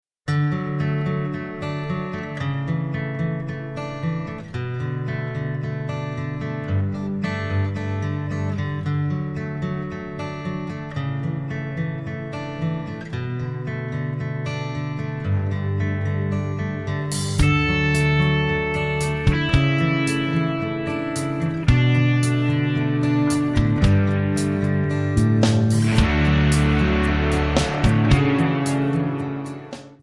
Buy With Backing Vocals.
Buy With Lead vocal (to learn the song).